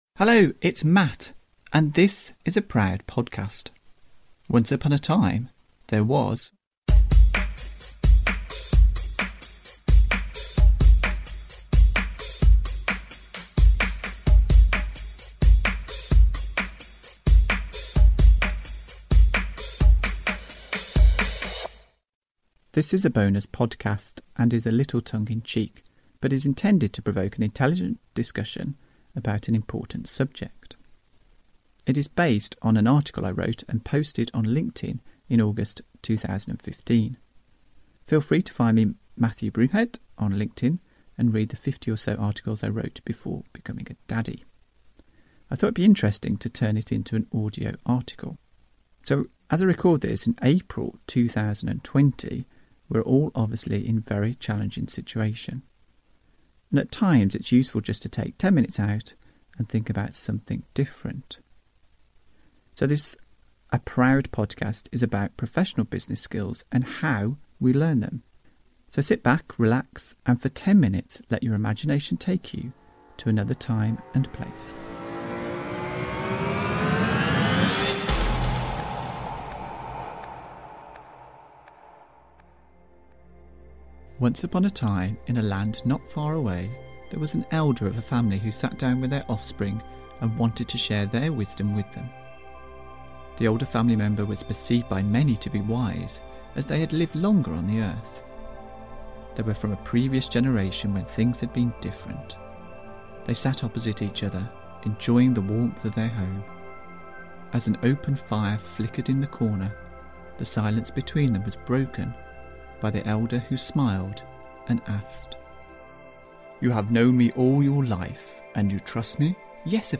pre-2008 (using Roland 303) & 2020 (using GarageBand).